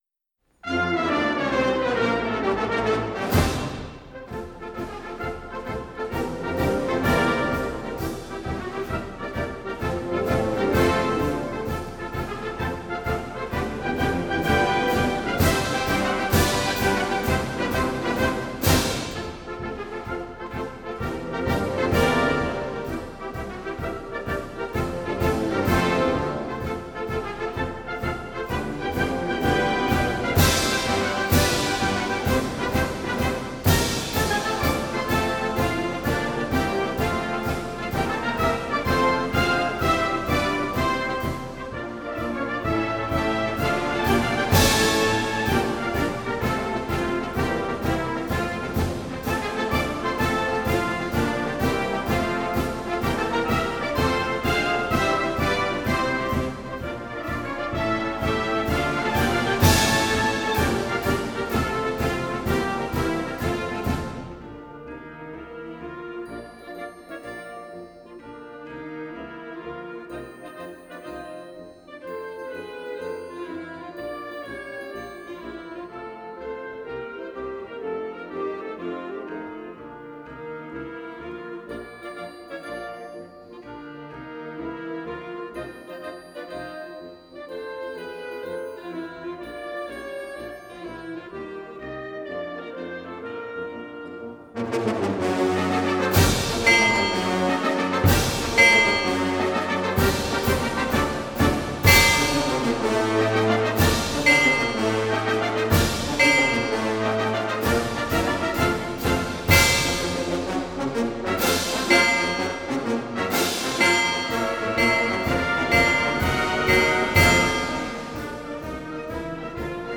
March, “The Liberty Bell”